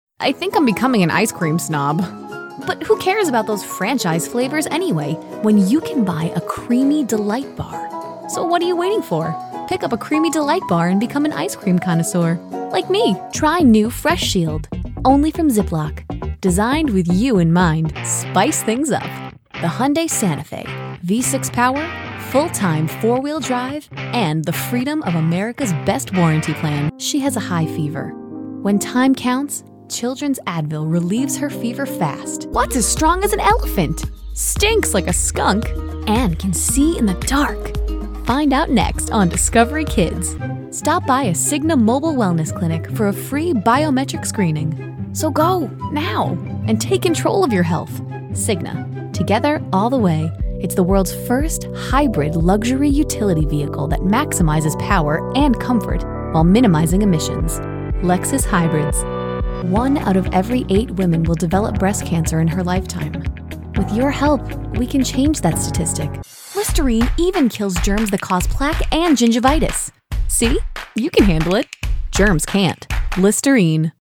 Female Voice Over, Dan Wachs Talent Agency.
Bright, Friendly, Warm, Credible.
Commercial